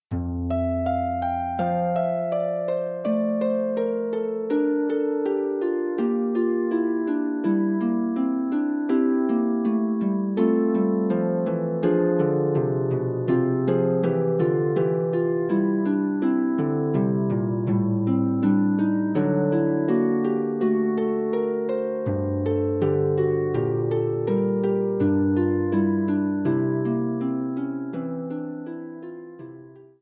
for solo pedal harp.